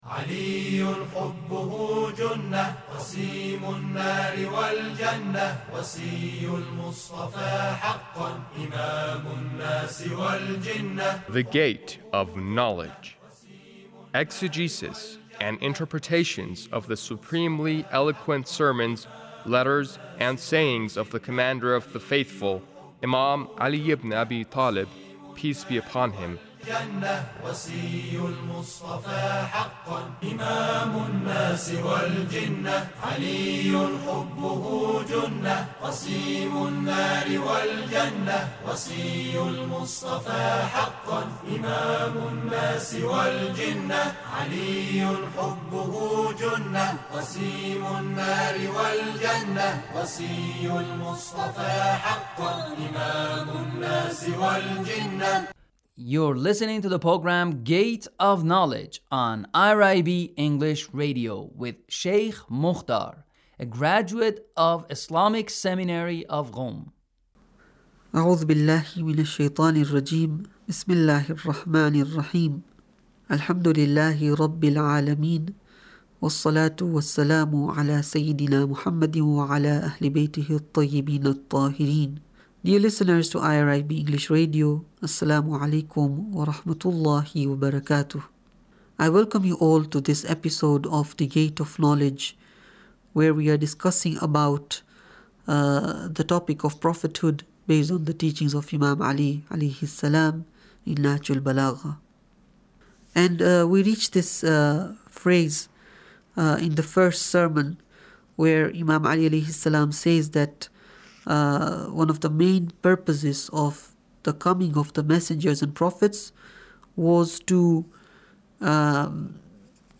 Sermon 1 - Prophethood 22